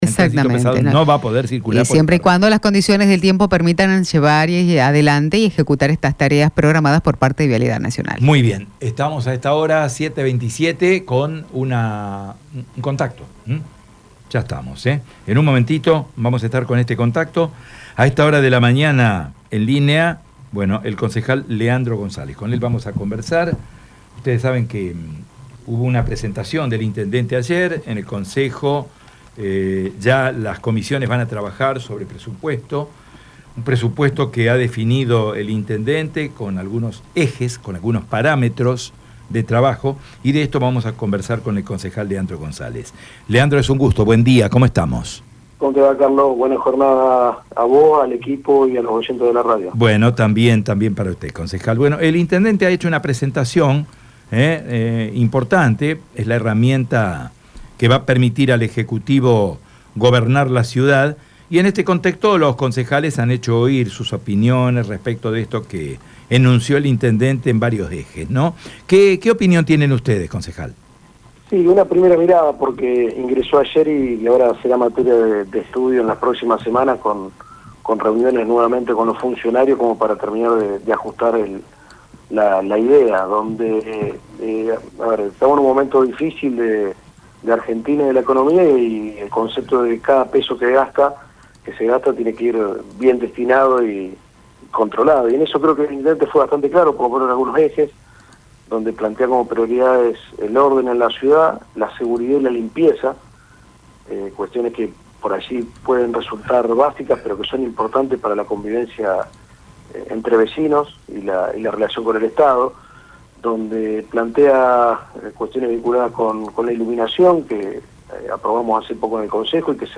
En ese contexto, Radio EME tomó contacto con el concejal Leandro Gonzalez.
Escucha la palabra del Concejal Leandro Gonzalez en Radio EME: